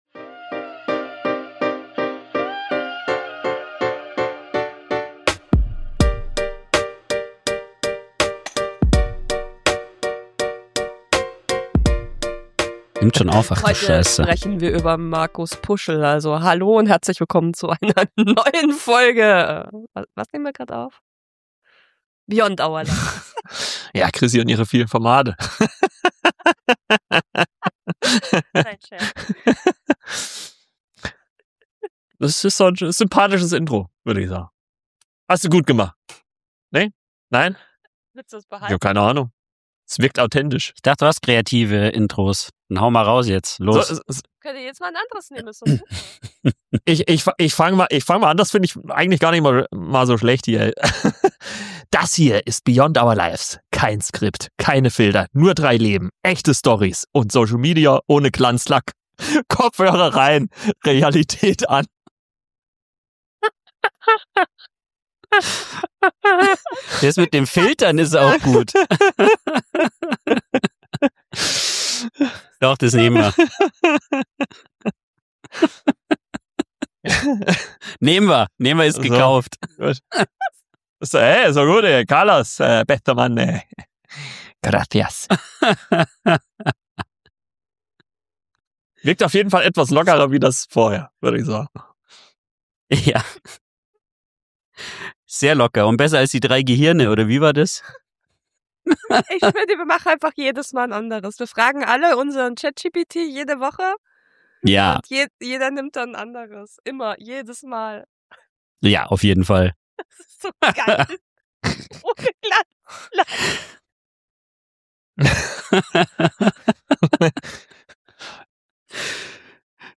Drei Hosts im Gespräch